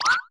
015-Jump01.opus